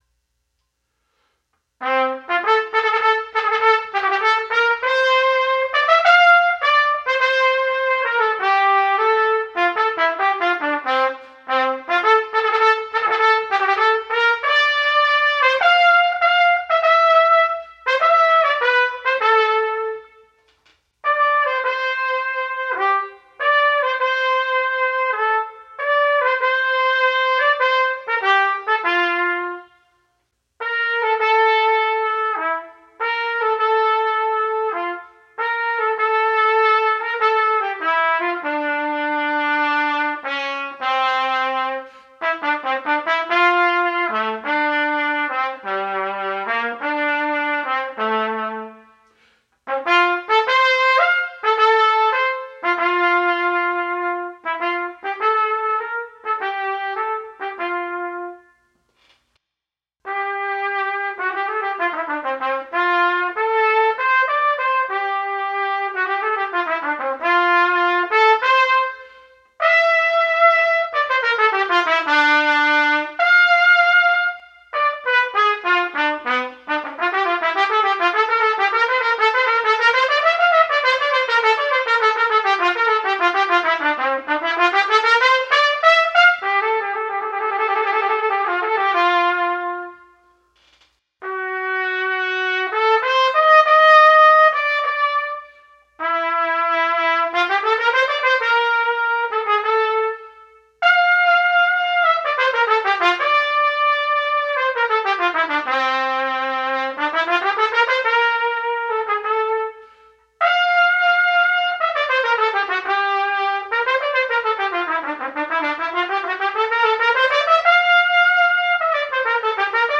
Trumpet
Video #2 – Voisin 11 Studies for Trumpet, No. 11